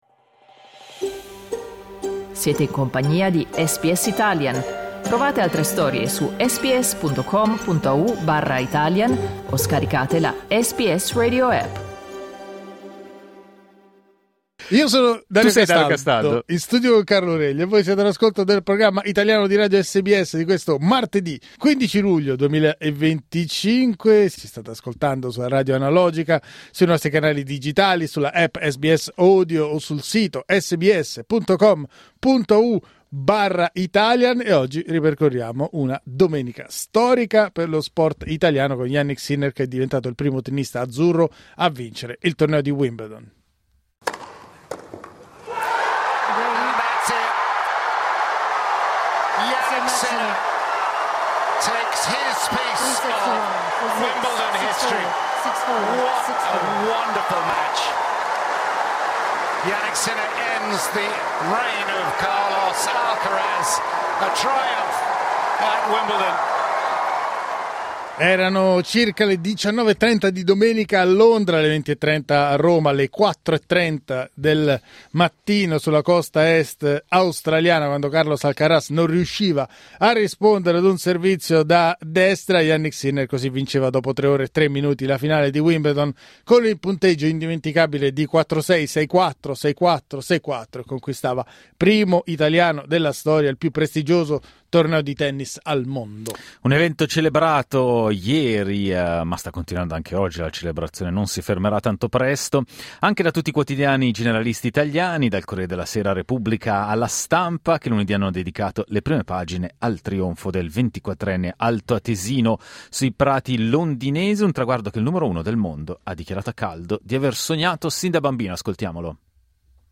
Il trionfo del tennista altoatesino a Wimbledon ha acceso il dibattito: nonostante la sua giovane età, la carriera di Sinner può essere già considerata migliore di quelle di Coppi, Mennea, Tomba e Valentino Rossi? Lo abbiamo chiesto agli ascoltatori di SBS.
Clicca il tasto 'play' in alto per ascoltare il nostro dibattito di martedì 15 luglio 2025